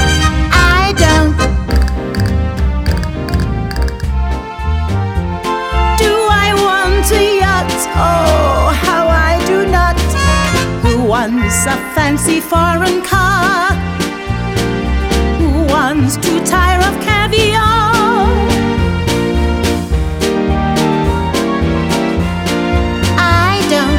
For Solo Male Duets 2:07 Buy £1.50